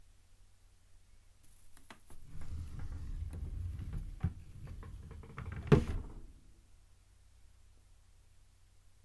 Slow/Close drawer 4
Duration - 9 s Environment - Bedroom, Absorption eg carpet, curtain, bed. Description - Wooden drawer, closing, recorder is on the floor in central position.